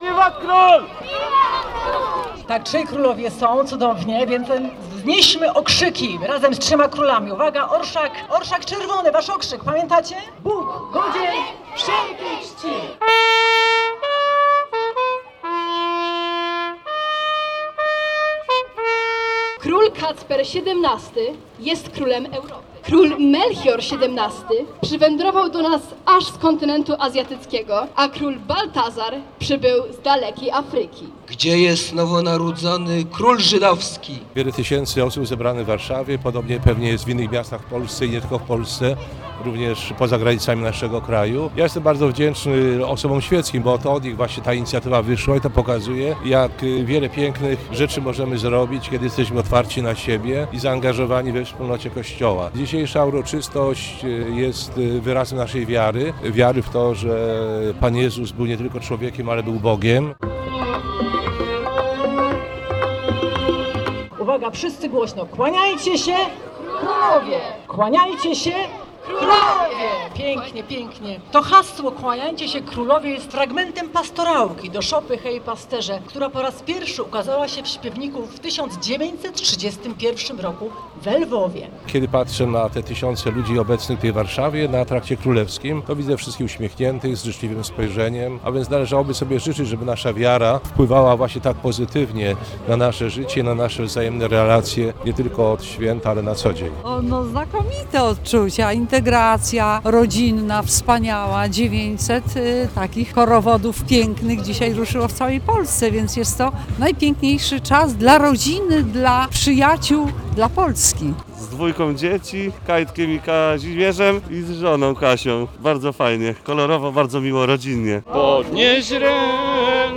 „Kłaniajcie się królowie!” to hasło tegorocznego Orszaku Trzech Króli, który ruszył dziś o godzinie 12:00 spod pomnika Kopernika na Krajowskim Przedmieściu. W korowodzie poza władcami chcącymi oddać hołd narodzonemu Jezusowi przeszli liczni kolędnicy, pastuszkowie, a nawet pluszowe wielbłądy i dwa azjatyckie smoki.